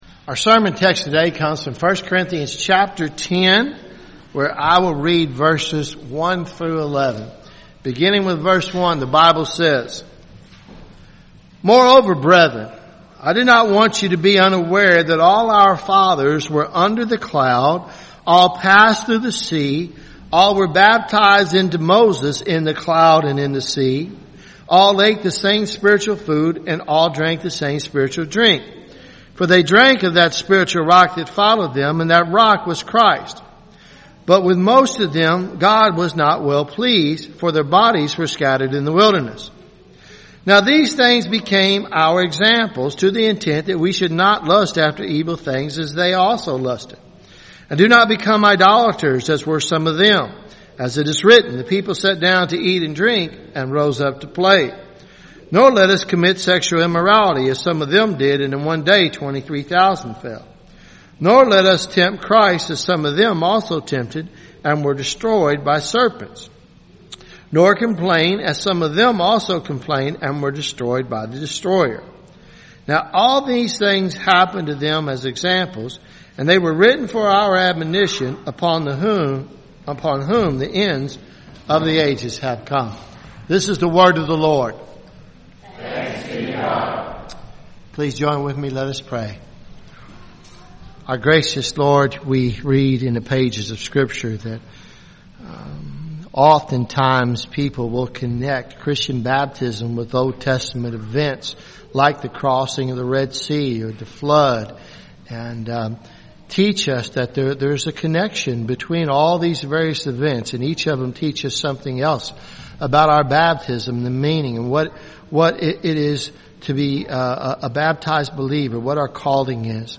Sermons Apr 15 2018 “Crossing the Sea